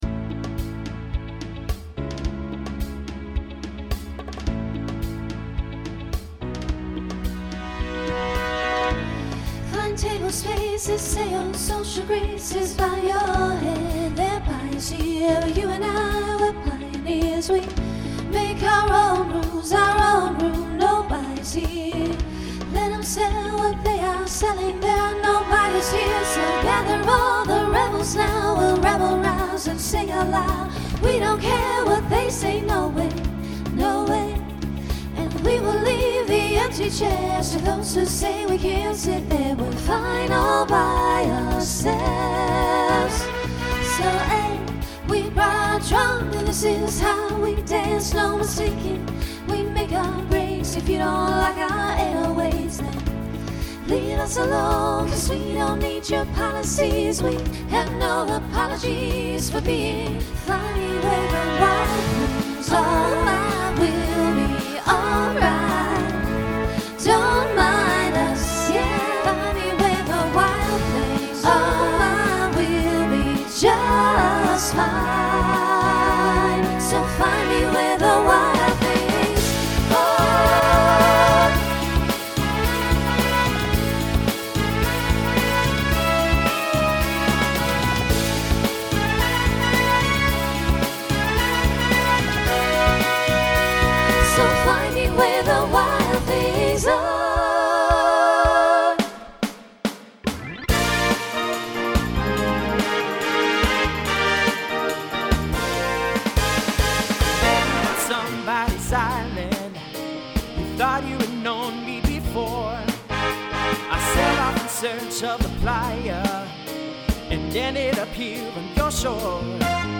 SSA/TTB
Voicing Mixed Instrumental combo Genre Rock